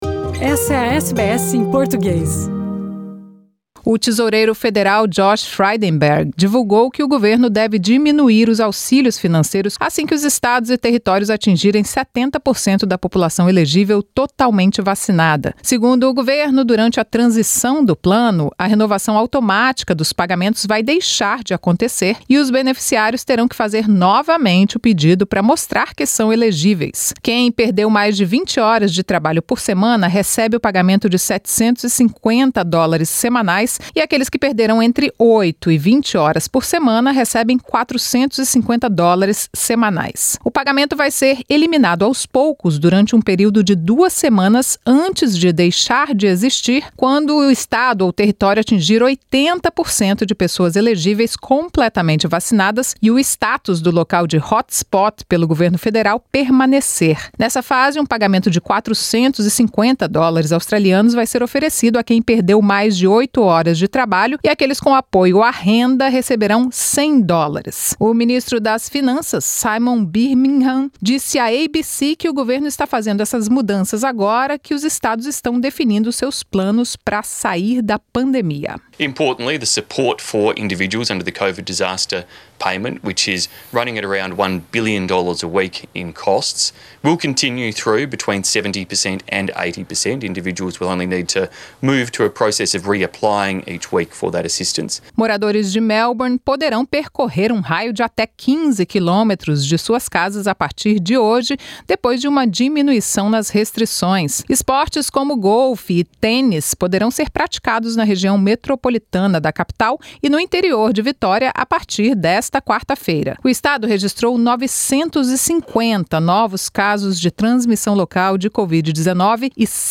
Presidente francês Emmanuel Macron diz estar pronto para conversar com Scott Morrison após fim do acordo bilionário de compra de submarinos. Alemanha elege parlamento mais inclusivo da história. São as notícias da Austrália e do Mundo da Rádio SBS para esta quarta-feira.